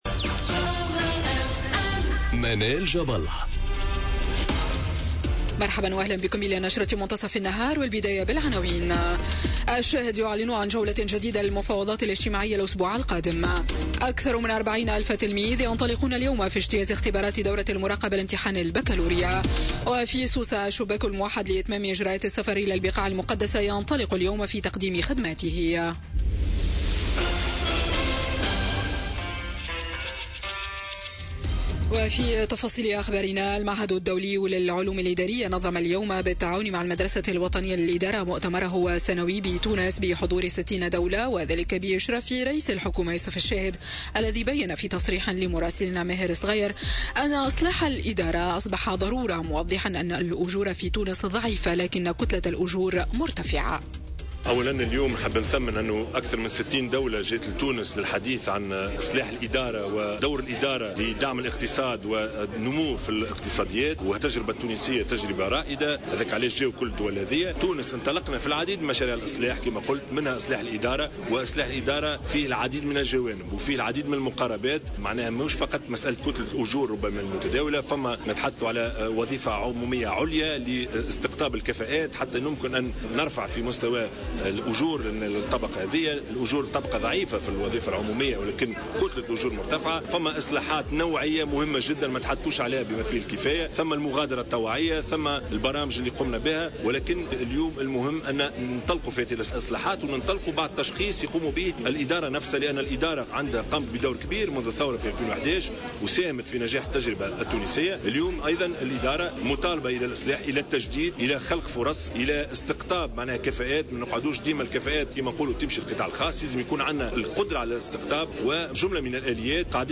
Journal Info 12h00 du mardi 26 Juin 2018